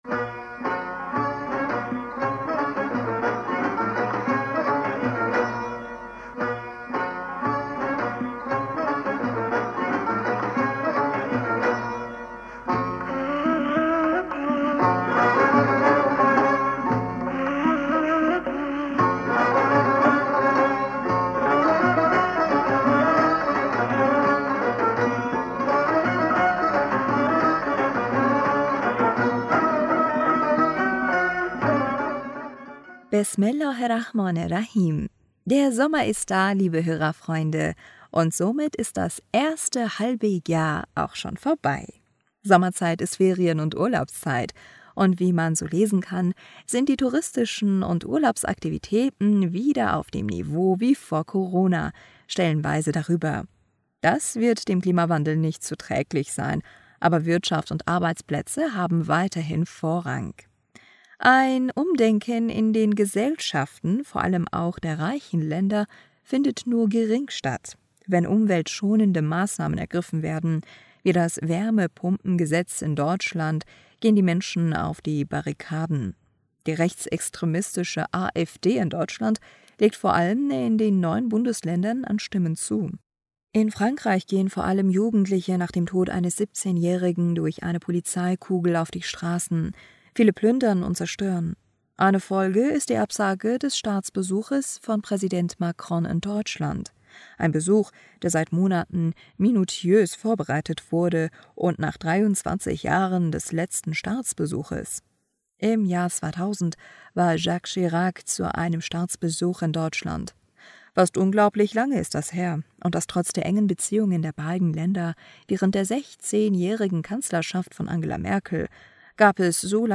Hörerpostsendung am 2.Juli 2023.